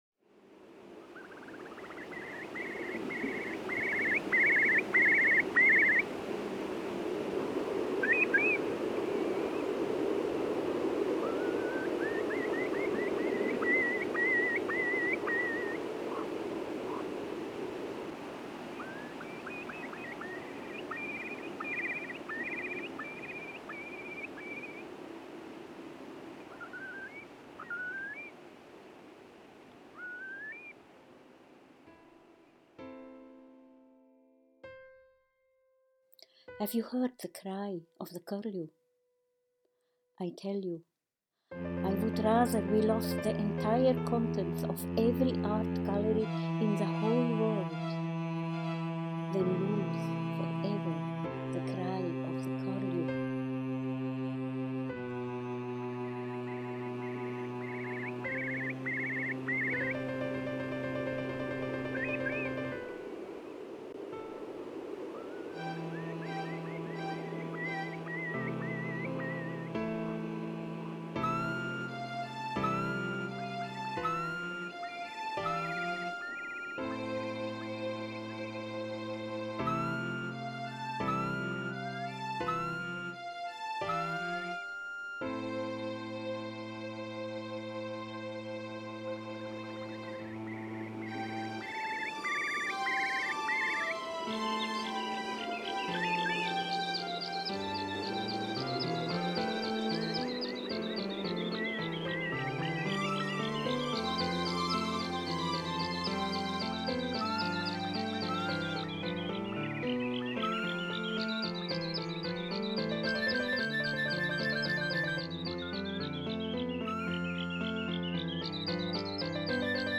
Click here to hear the sound set to music